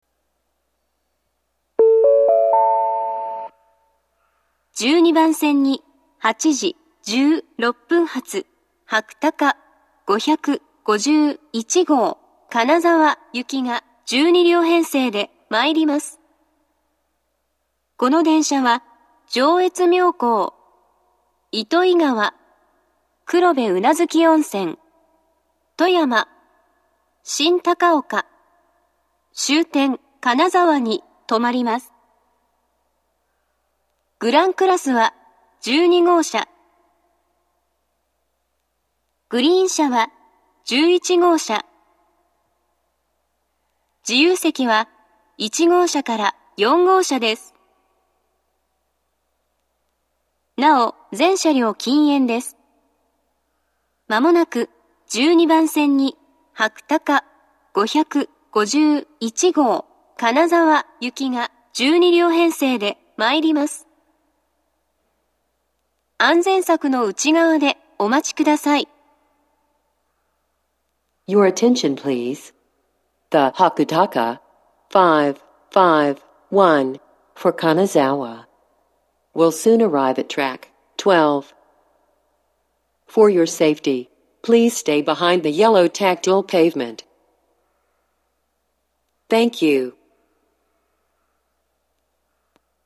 １２番線接近放送